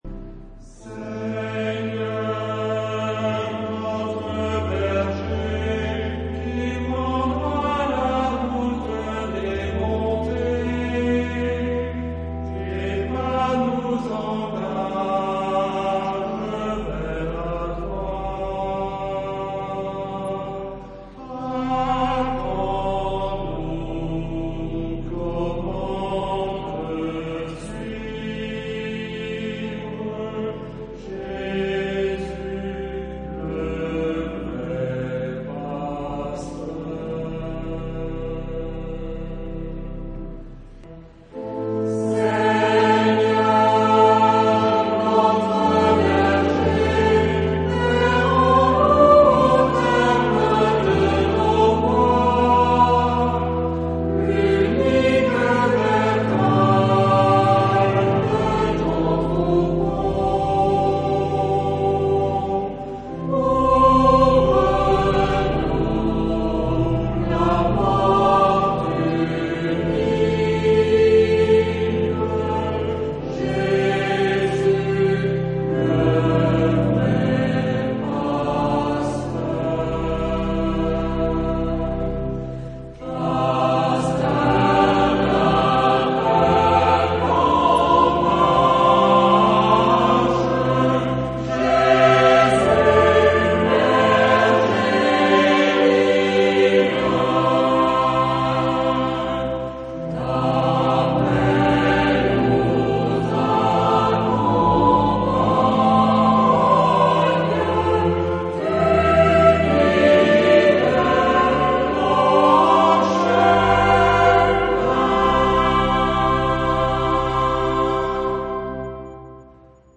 Genre-Style-Forme : Psaume
Caractère de la pièce : méditatif ; calme
Type de choeur : unisson
Instrumentation : Orgue
Tonalité : la mineur